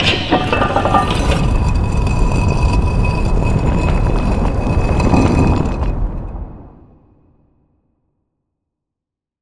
Secret door.wav